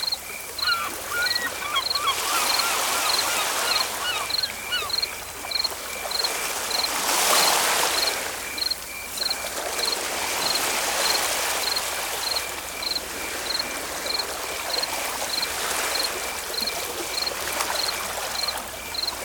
Ambiance Soirée côtière (Broadcast) – Le Studio JeeeP Prod
Bruits d’ambiance bord de mer au soleil couchant avec les mouettes.